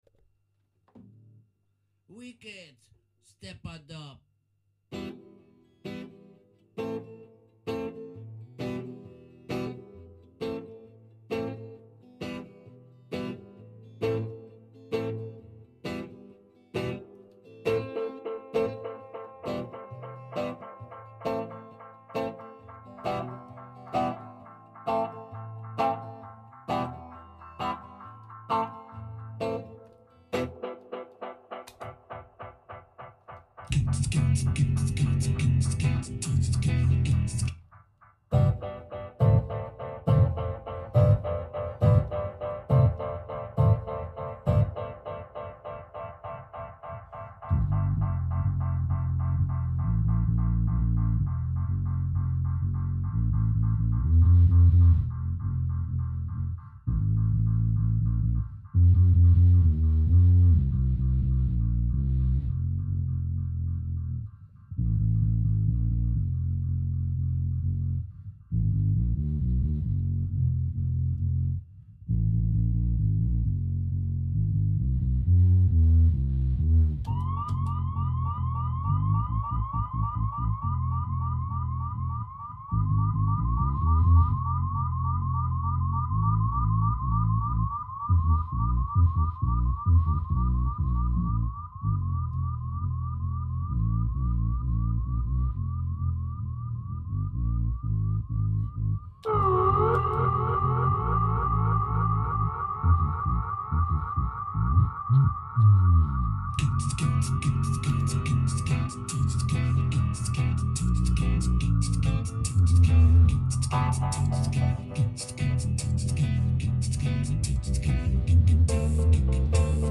soundsystem style hard steppa